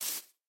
snapshot / assets / minecraft / sounds / step / grass3.ogg
grass3.ogg